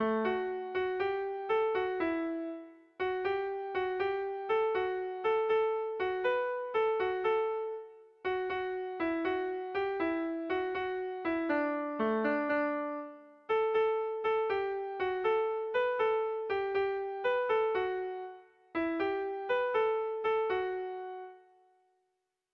Melodías de bertsos - Ver ficha   Más información sobre esta sección
Kontakizunezkoa
Zortziko txikia (hg) / Lau puntuko txikia (ip)
ABDE